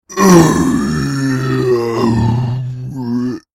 Zombie Growl Halloween - Botão de Efeito Sonoro